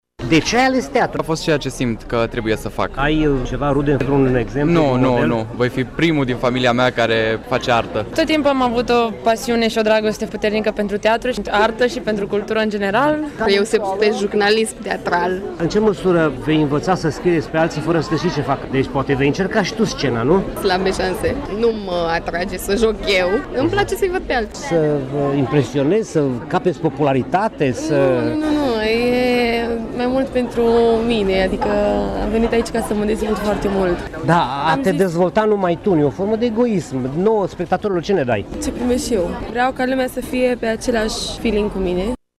Veniți din toate colțurile țării, emoționați, bobocii păreau că știu ce vor de la viitoarea lor meserie artistică: